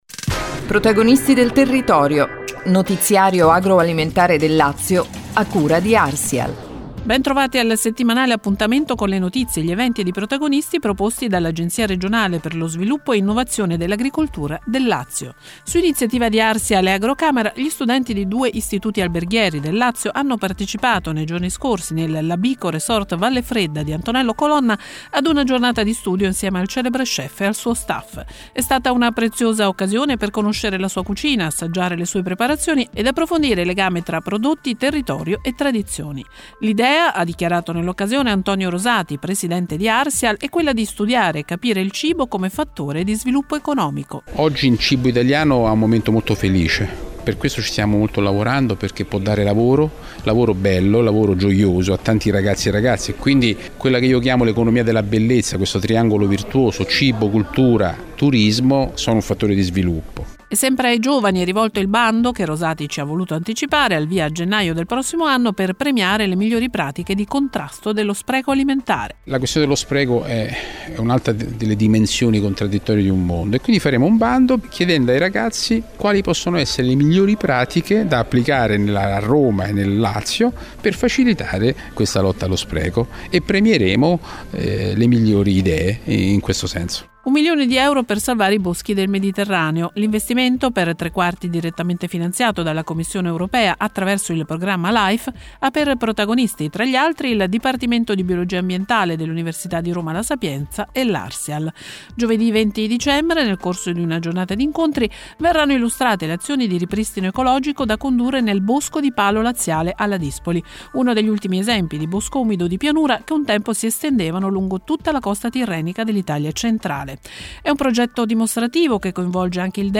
“Protagonisti del territorio”, notiziario radiofonico Arsial